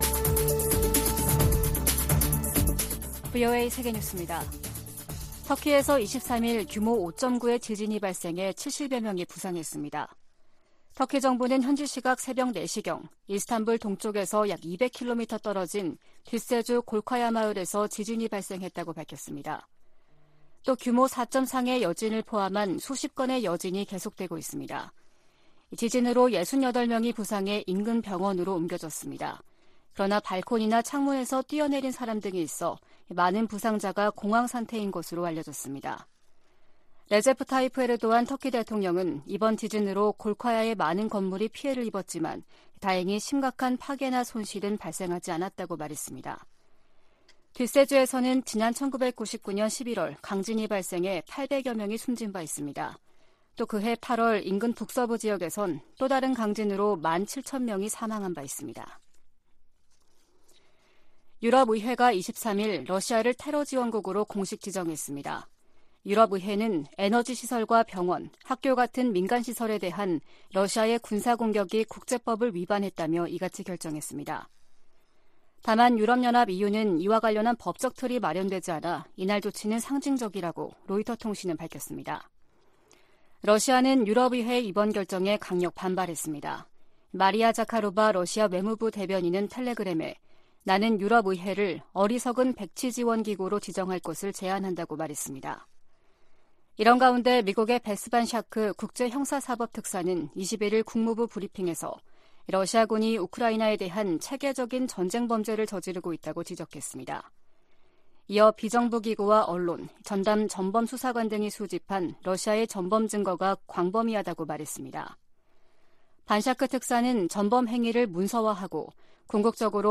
VOA 한국어 아침 뉴스 프로그램 '워싱턴 뉴스 광장' 2022년 11월 24일 방송입니다. 백악관의 존 커비 전략소통조정관은 중국이 북한에 도발적인 행동을 멈추도록 할 수 있는 압박을 가하지 않고 있다고 지적했습니다. 중국이 북한 불법무기 프로그램 관련 유엔 안보리 결의를 전면 이행해야 한다고 미 국방장관이 촉구했습니다.